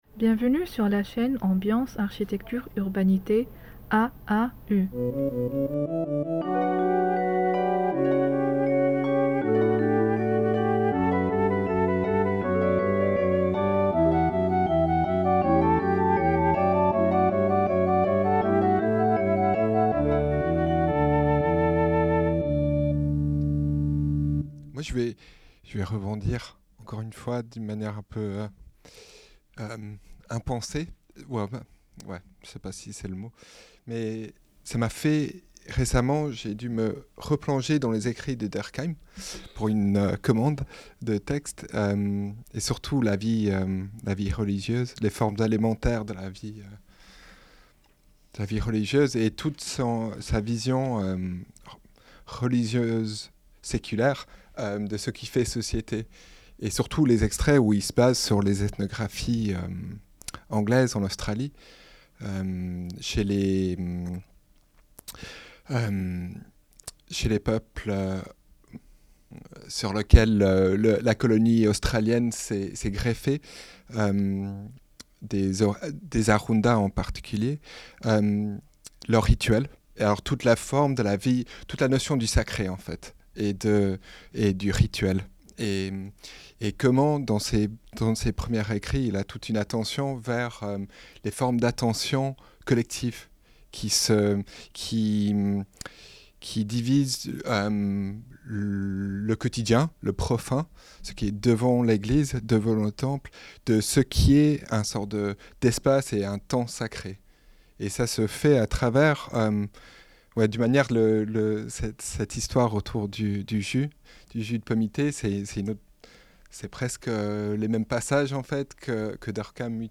Les énigmes du sensible 4 : discussion collective | Canal U
Discussion qui prend lieu à la fin du quatrième séminaire du projet ANR Sensibilia : vers un care ambiantal ?